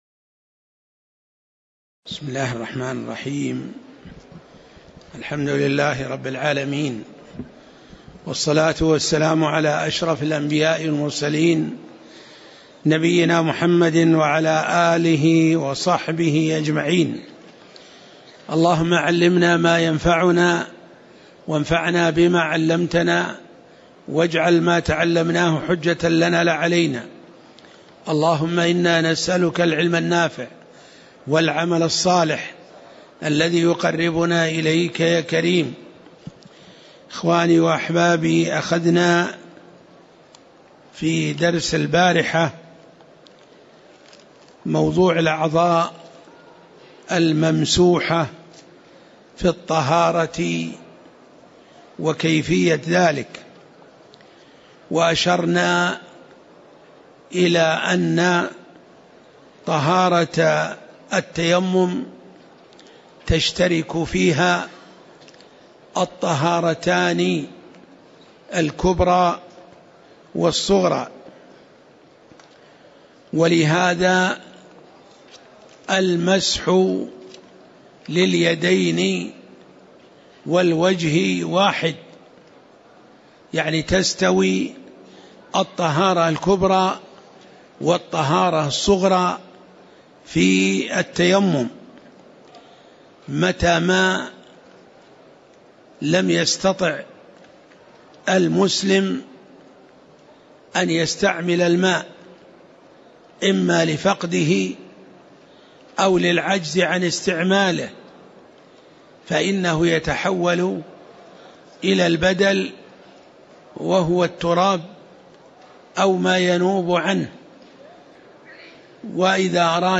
تاريخ النشر ١٥ شوال ١٤٣٨ هـ المكان: المسجد النبوي الشيخ